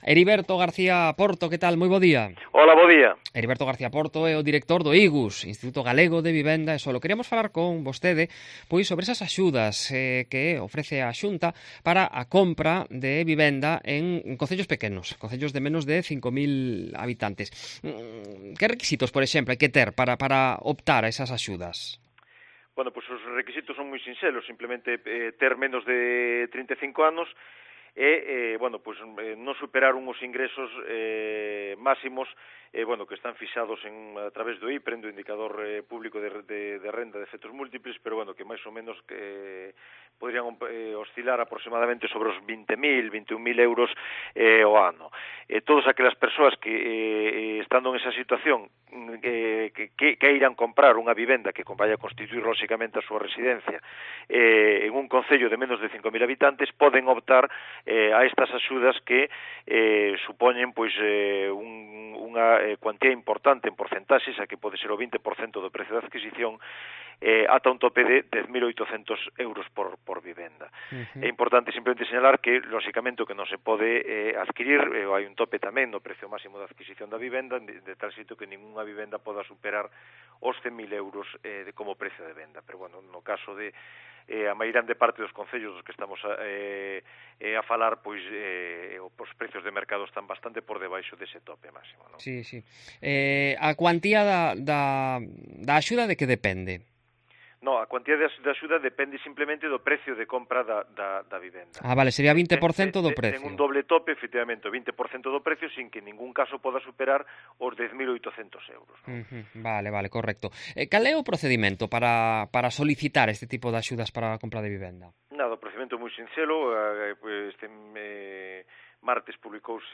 Entrevista al director del IGVS sobre las ayudas para la compra de viviendas en municipios pequeños